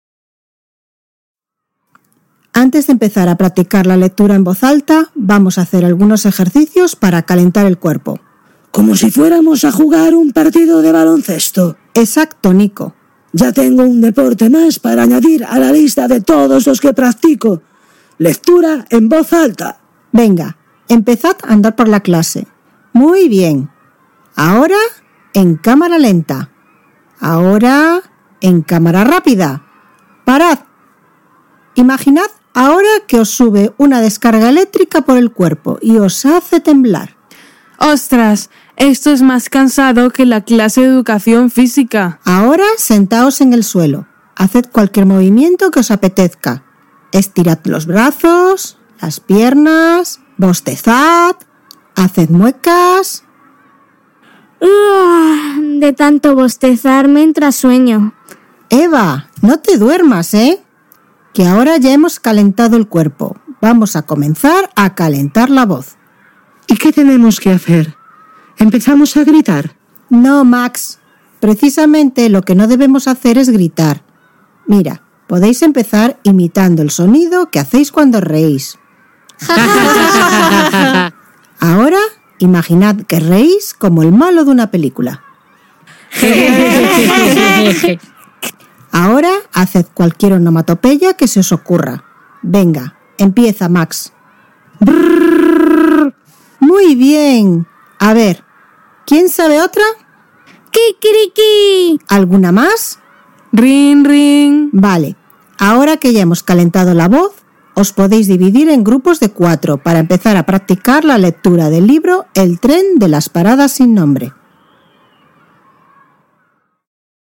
ESCUCHAMOS UNA CONVERSACIÓN | TEMA 9 LENGUA 6º